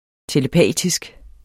Udtale [ teləˈpæˀtisg ]